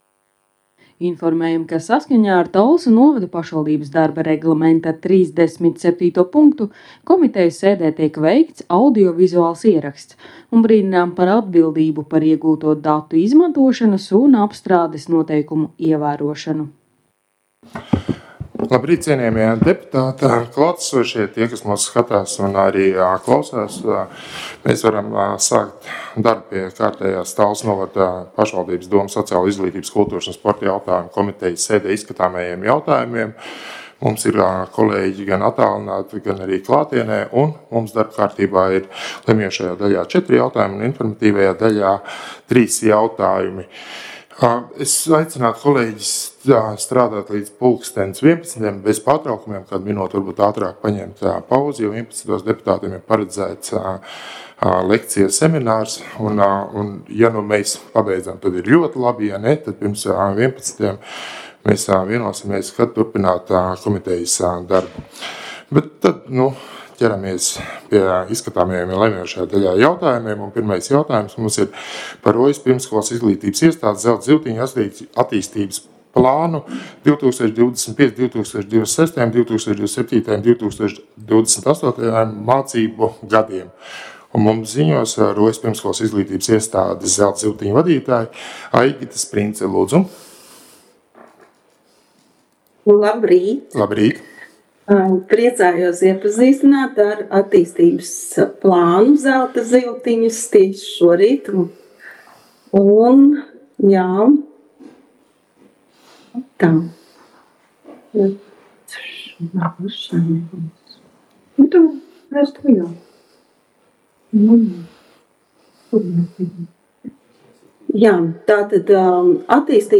Komitejas sēdes audio